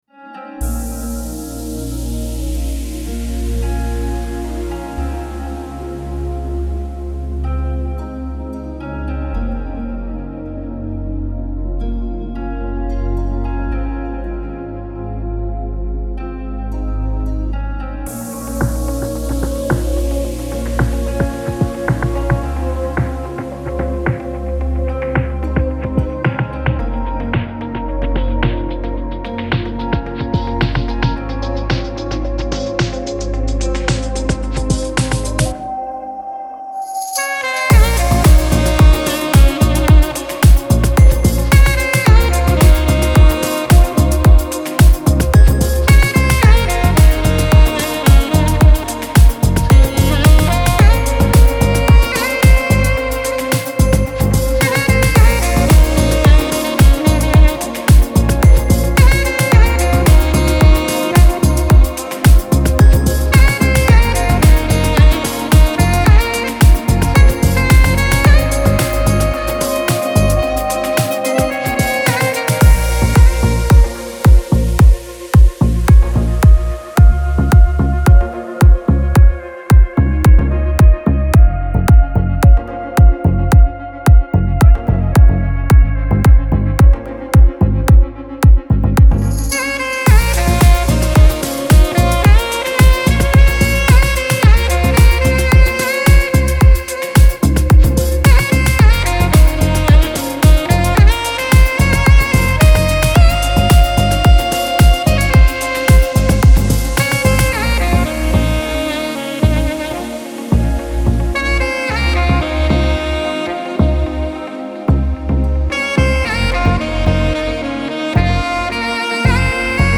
رقص , ریتمیک آرام , موسیقی بی کلام